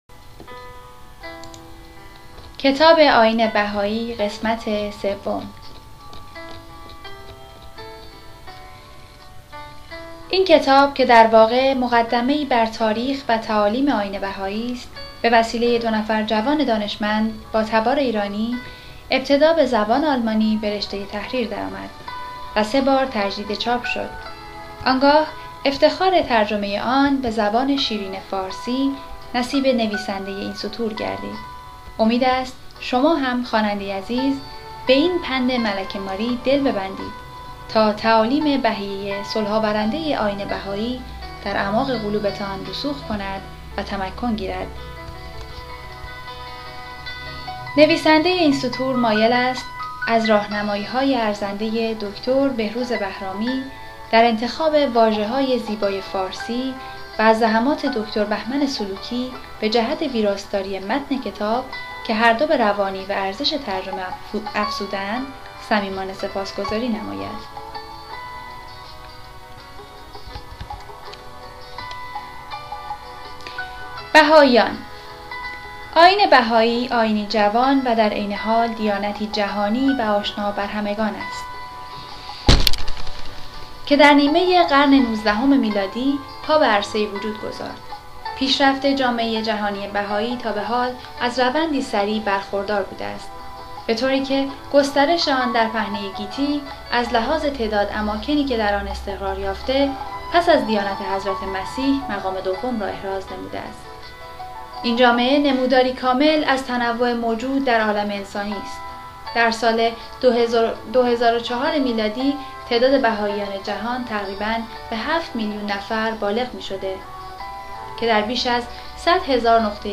کتابهای صوتی